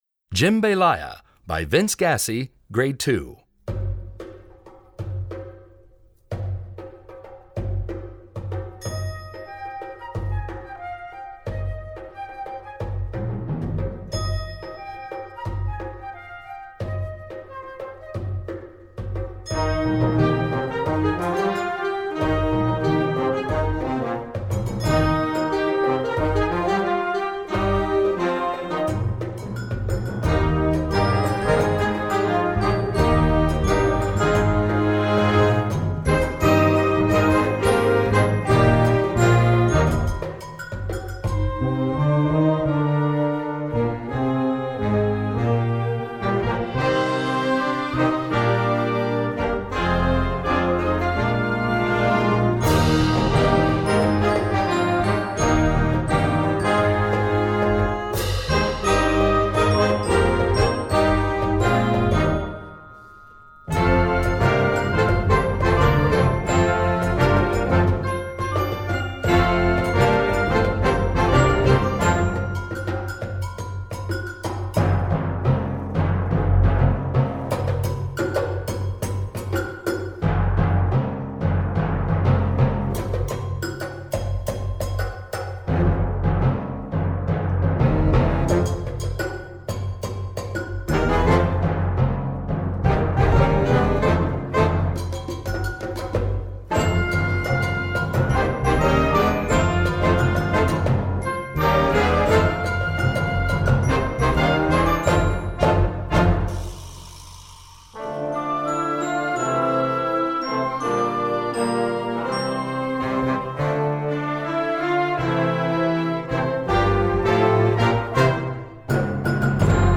Gattung: Werk für Jugendblasorchester
Besetzung: Blasorchester